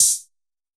UHH_ElectroHatD_Hit-14.wav